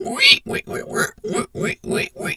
pig_2_hog_seq_06.wav